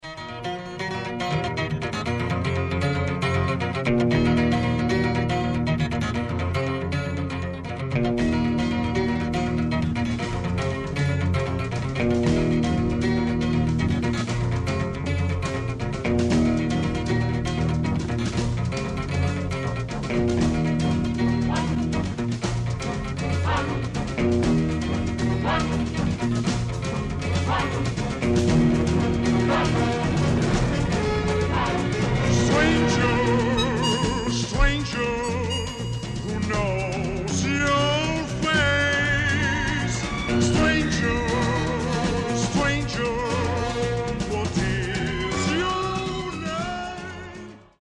Spaghetti Western exciting medium voc.